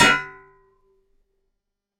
Metal Clang
A heavy metal object striking another with a bright, resonant clang and ring-out
metal-clang.mp3